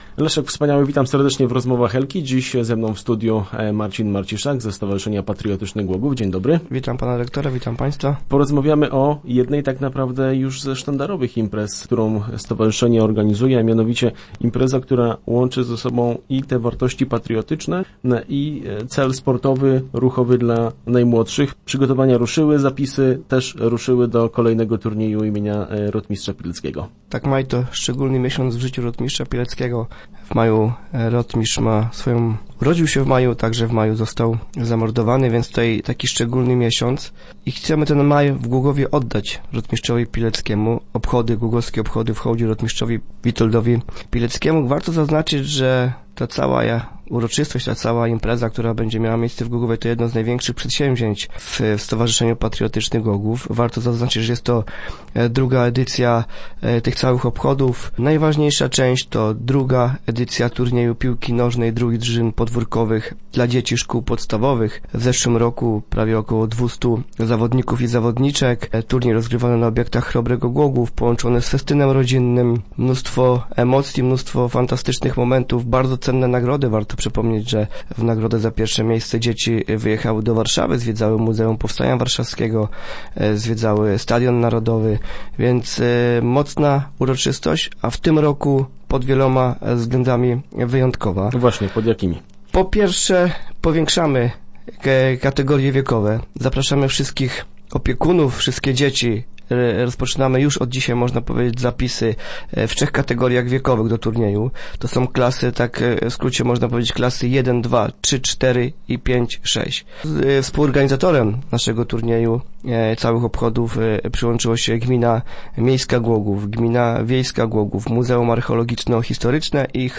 Start arrow Rozmowy Elki arrow Minister Edukacji przyjedzie do Głogowa